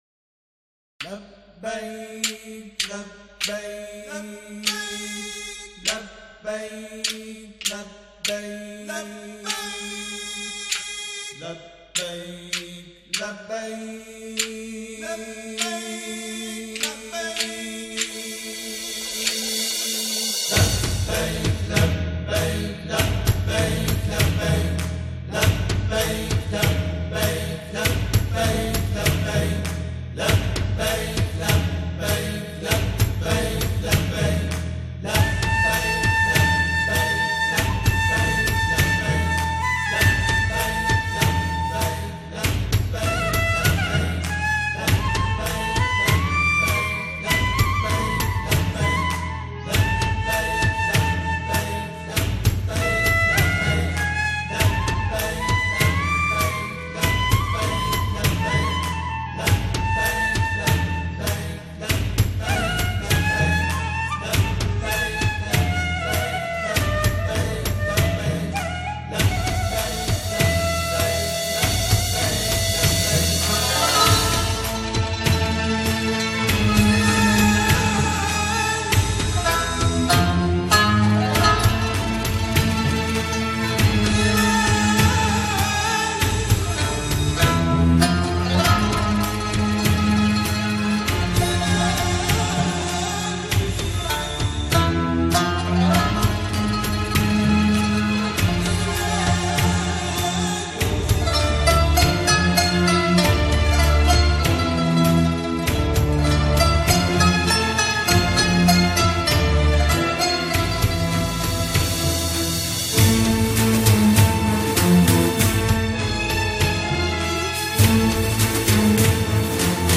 گروهی از همخوانان
جمعخوانی